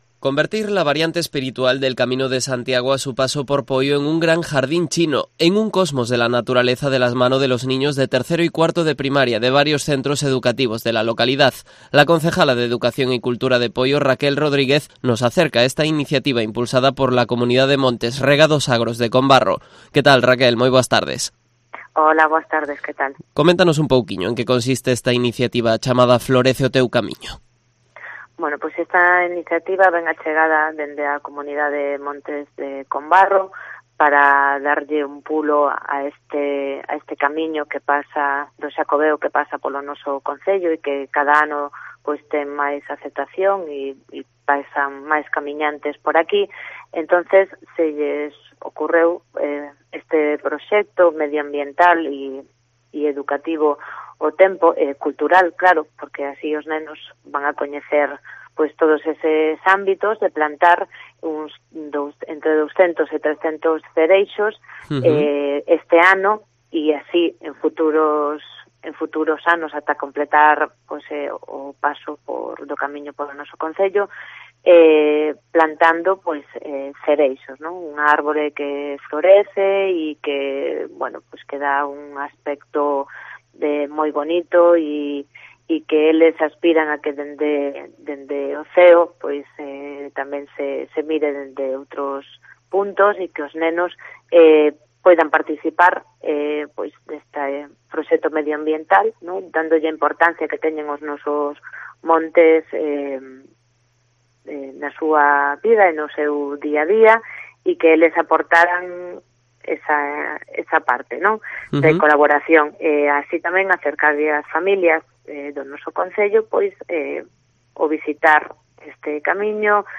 Entrevista de la concejala de Educación de Poio, Raquel Rodríguez, en Cope Pontevedra